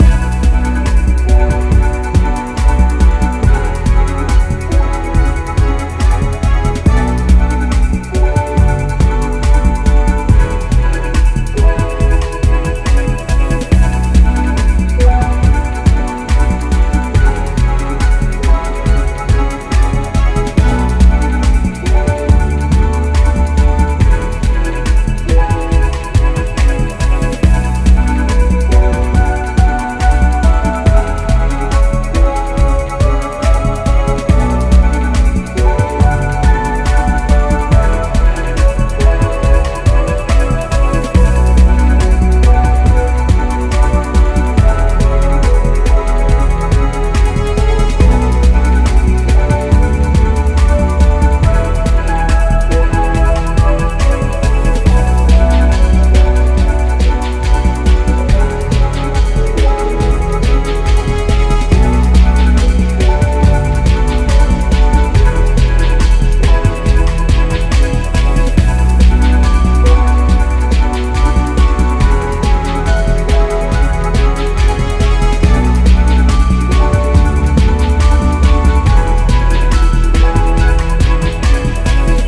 Little bouncy tune.
Genre Electronica